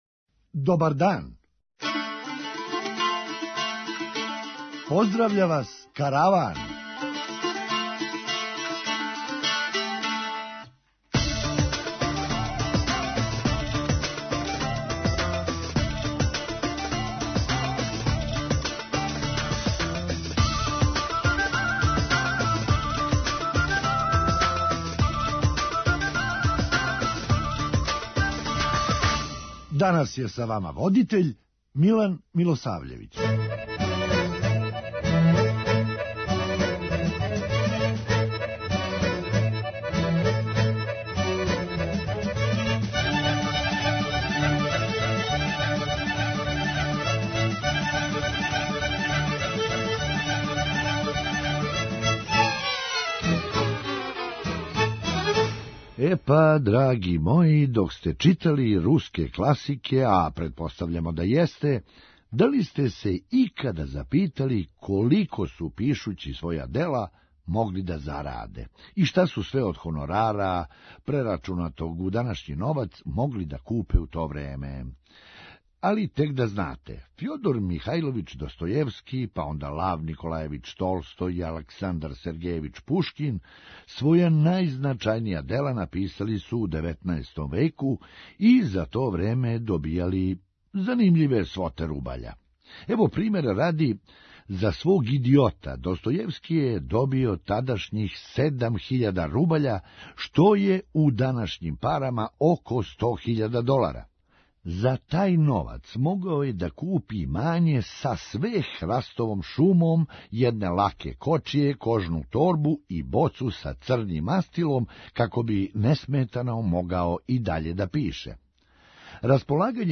Хумористичка емисија
То ће бити крими-серија са примесама хумора и хорора! преузми : 9.11 MB Караван Autor: Забавна редакција Радио Бeограда 1 Караван се креће ка својој дестинацији већ више од 50 година, увек добро натоварен актуелним хумором и изворним народним песмама.